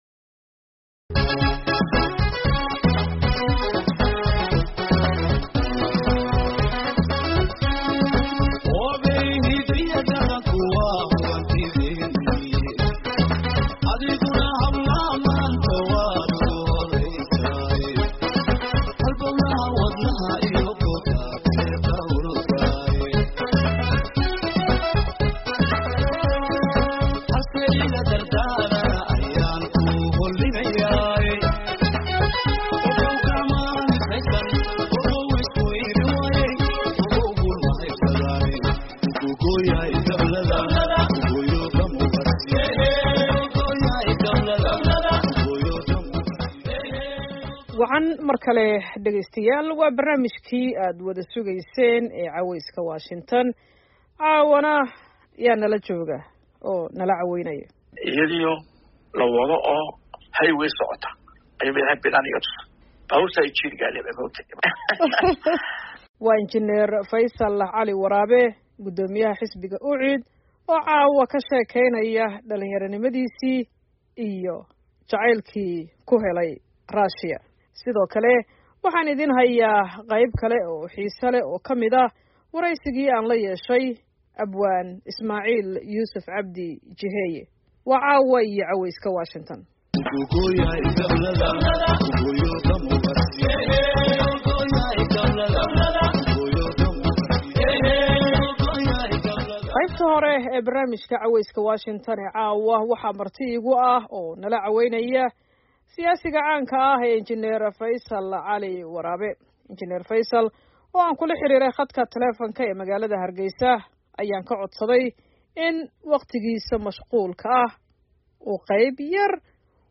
Qodobada aad ku maqli doontaan waxaa ka mid wareysi uu barnaamijka Caweyska Washington siiyay Guddoomiyaha Xisbiga UCID ee Somaliland Inj. Faysal Cali Waraabe